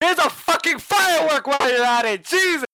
Fireworks